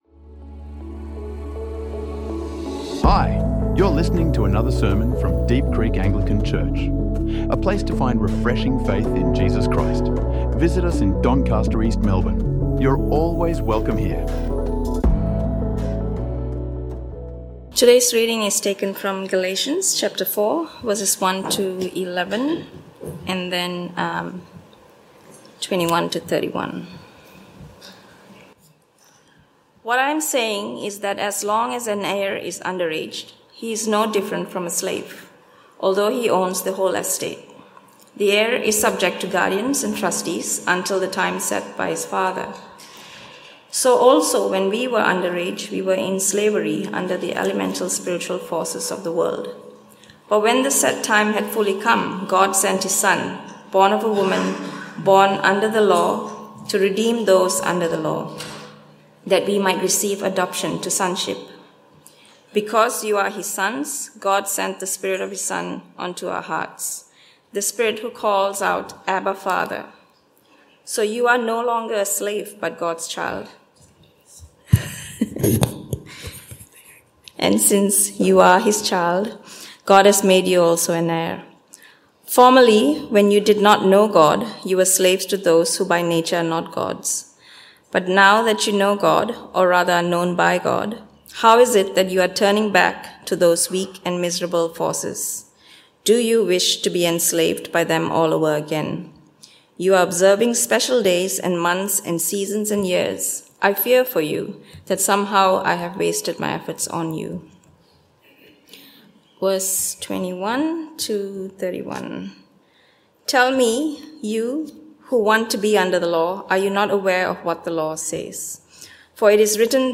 Slaves or Sons | Sermon | Deep Creek Anglican Church